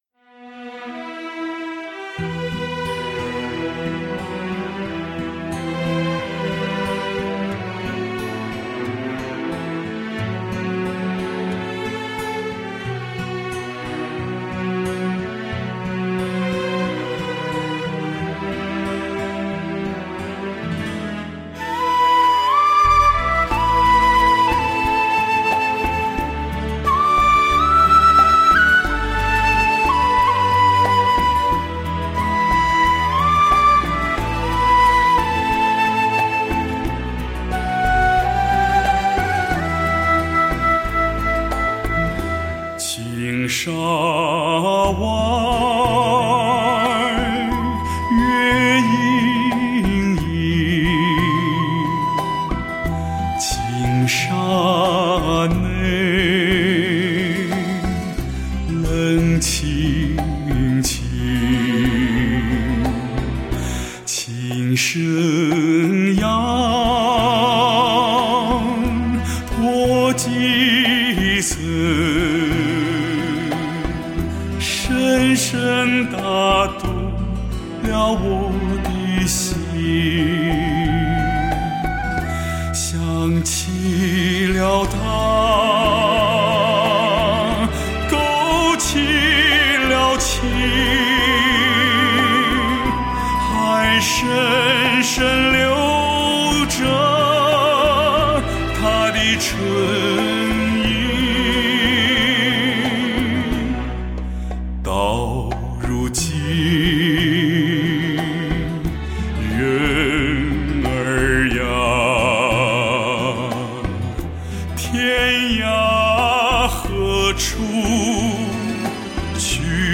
亚洲第一男中音